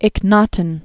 (ĭk-nätn)